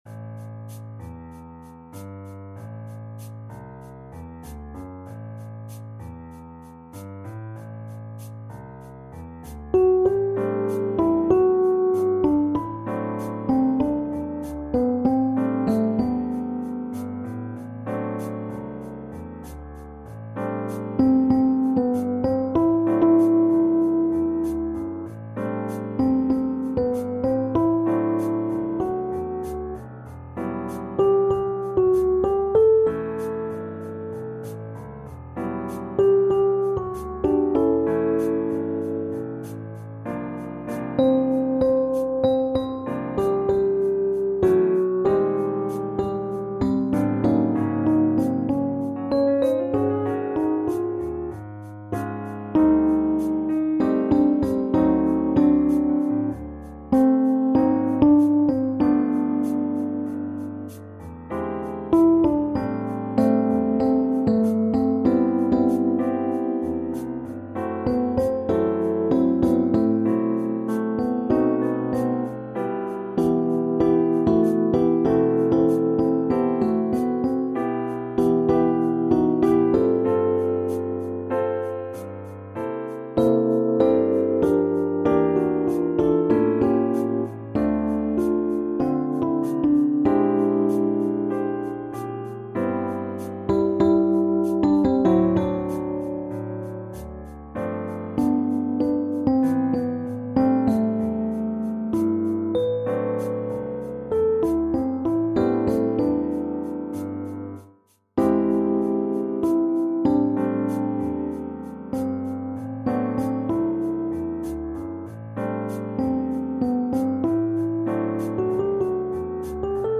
SATB met piano | SSAB met piano